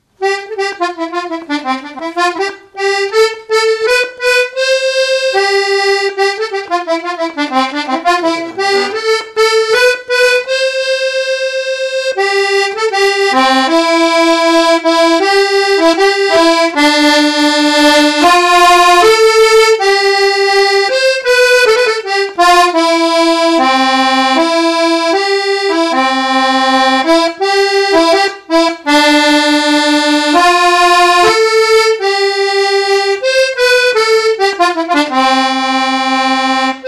Marche
Chapelle-Thémer (La)
circonstance : fiançaille, noce
Pièce musicale inédite